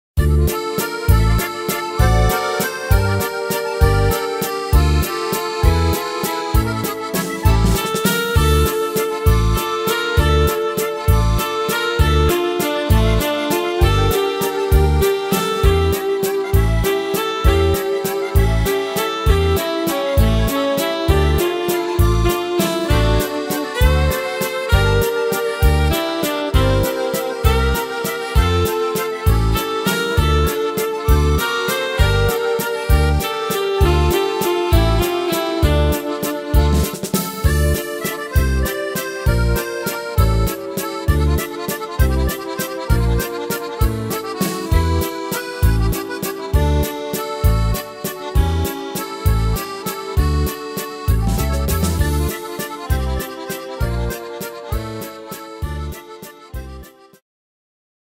Tempo: 198 / Tonart: Dm